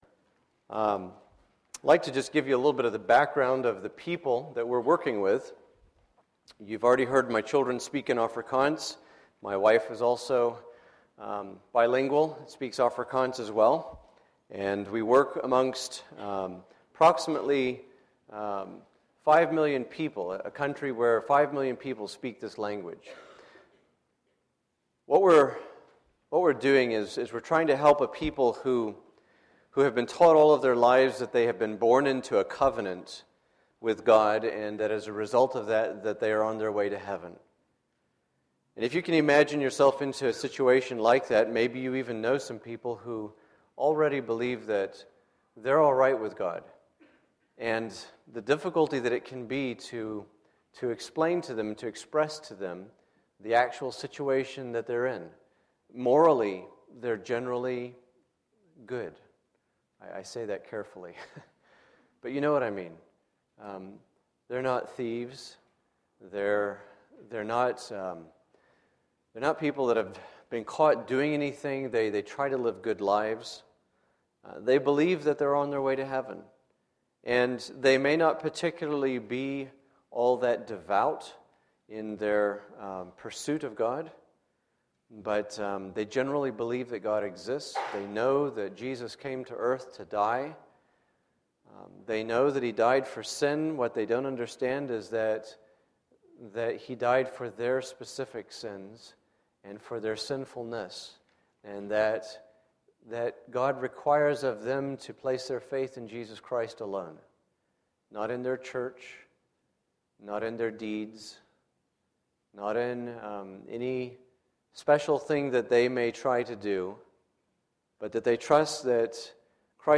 Sunday, July 22, 2012 – Morning Message